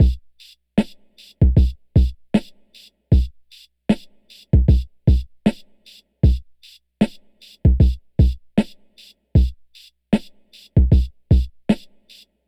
Drum Loop 1.wav